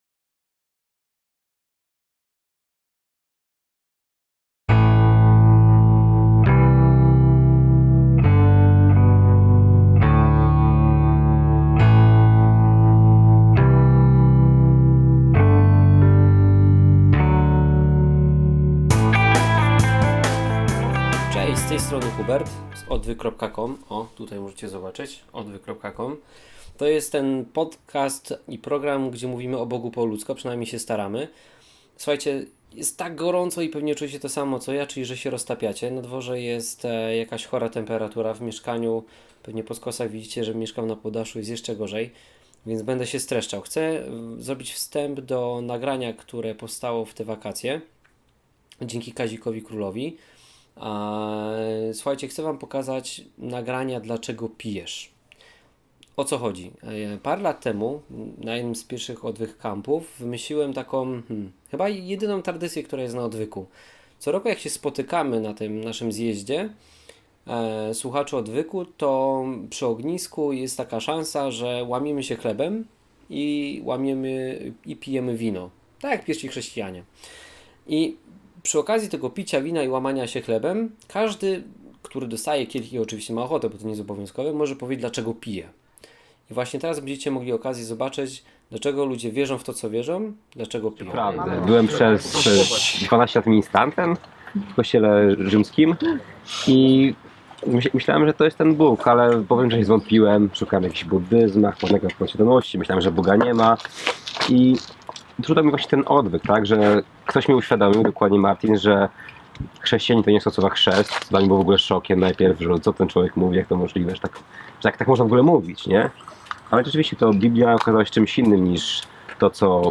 Zebrała się grupa młodych,wspaniałych osób, którzy mają niesamowity kontakt z Bogiem.